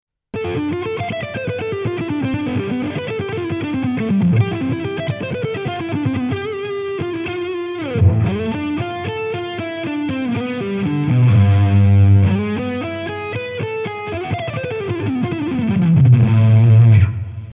neo-classical
Valve Amp on Channel 3 Boss DS1 Ibanez
Shred